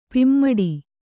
♪ pimmaḍi